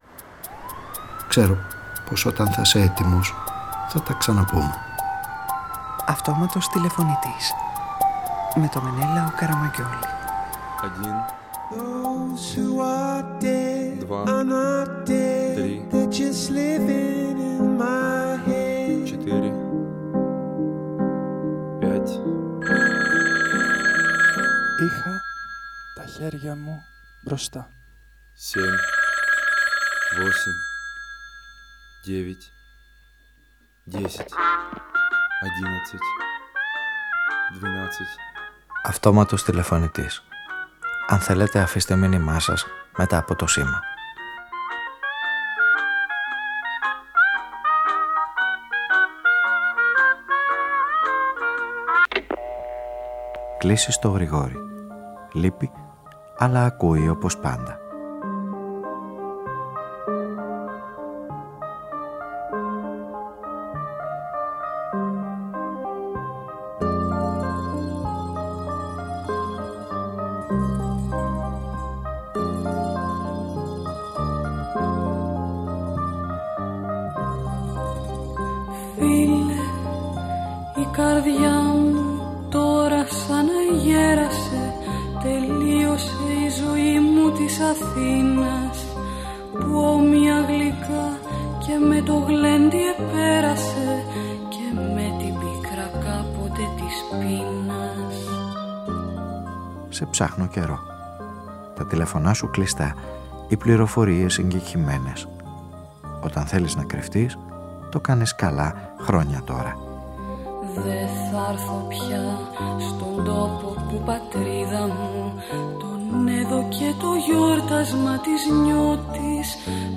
Μια ραδιοφωνική ιστορία που προσπαθεί να επαναφέρει έναν απαραίτητο φίλο που λείπει, διεκδικεί τη διάρκεια στις σχέσεις και κατοχυρώνει ισόβιες (μακάρι και πέραν αυτού) εταιρικές σχέσεις αγάπης απενοχοποιώντας την έννοια του εταίρου και διεκδικώντας τη συνέχεια.
Παραγωγή-Παρουσίαση: Μενέλαος Καραμαγγιώλης ΤΡΙΤΟ ΠΡΟΓΡΑΜΜΑ Αυτοματος Τηλεφωνητης Podcast στο Τρίτο